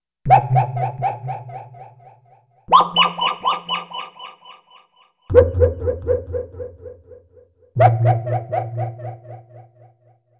描述：用REAKTOR制作的古怪循环和垫子
Tag: 92 bpm Weird Loops Fx Loops 3.50 MB wav Key : Unknown